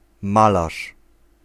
Ääntäminen
Synonyymit (halventava) barbouilleur Ääntäminen France: IPA: /pɛ̃tʁ/ Haettu sana löytyi näillä lähdekielillä: ranska Käännös Ääninäyte Substantiivit 1. malarz {m} Muut/tuntemattomat 2. malarka {f} Suku: m .